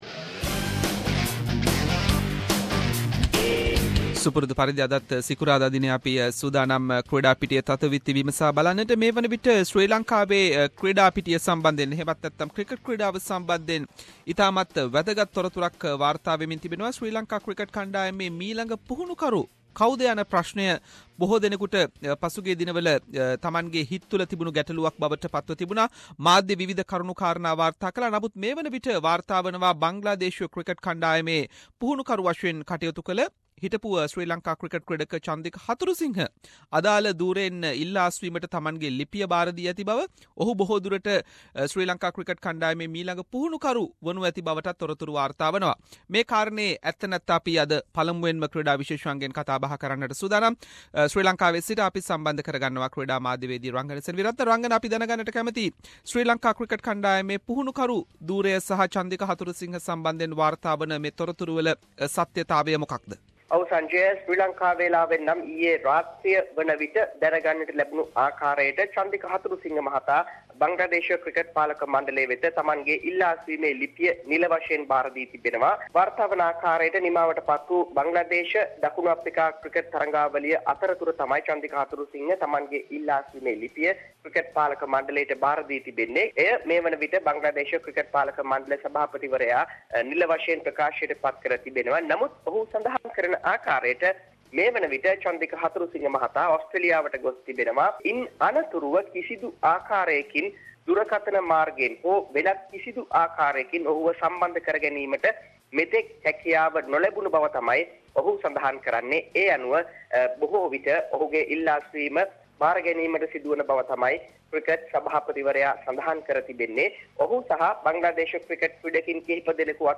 Sports journalist